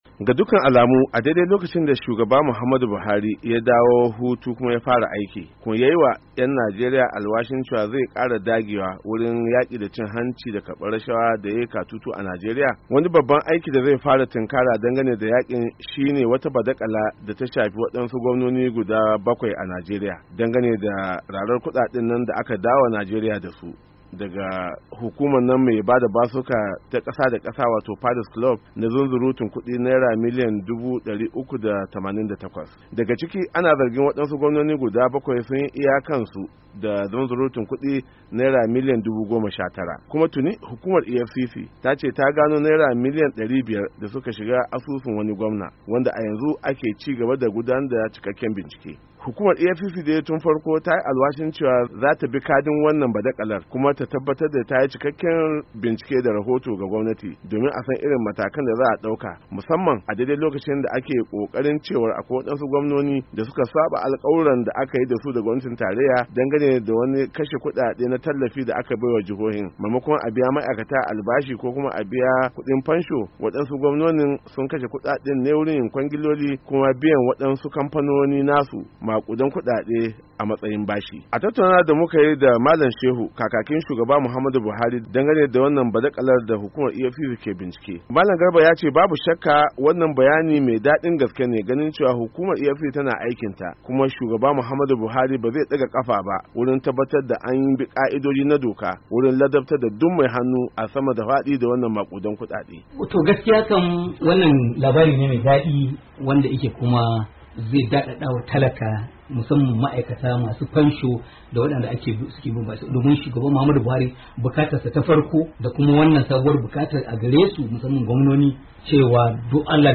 WASHINGTON, DC —